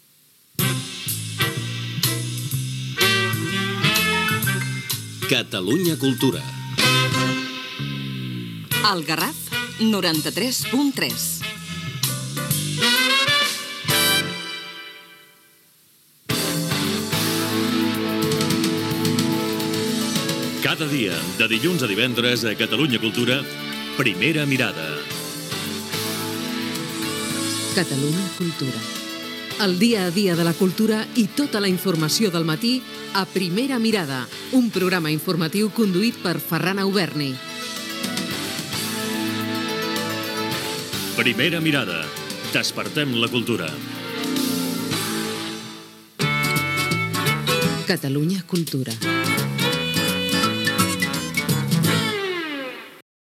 Indicatiu de l'emissora i promoció de "Primera mirada"